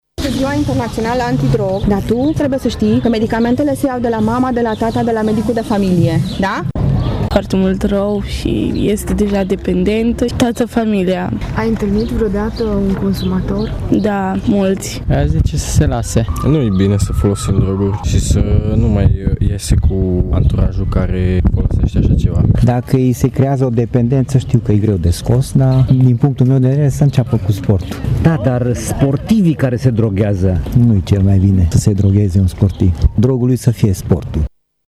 Deși lumea este conștientă că drogurile sunt nocive și pot avea efecte fatale, consumul lor ține de decizia fiecărei persoane în parte. Așa s-au exprimat participanții la acțiunea de informare de astăzi, desfășurată de Centrul Antidrog Mureș, la Complexul Weekend din Tîrgu-Mureș.